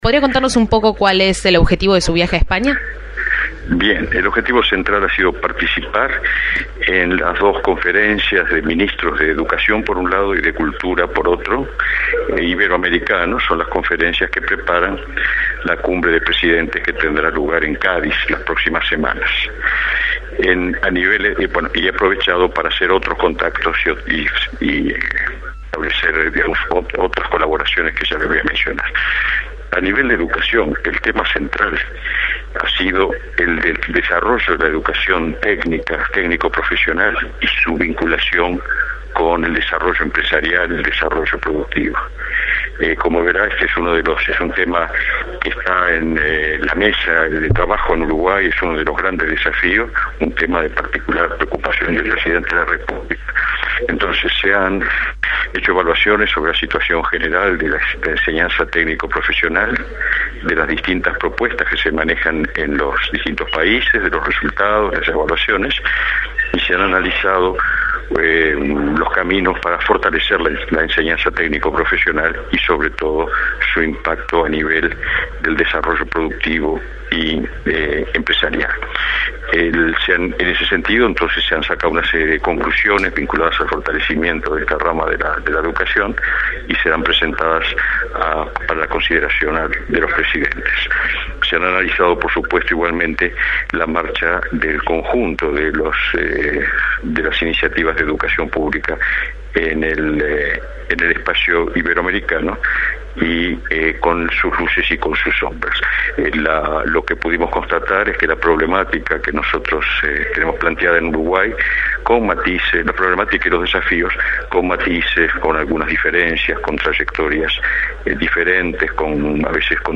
Entrevista al ministro Ehrlich